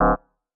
errorSFX.wav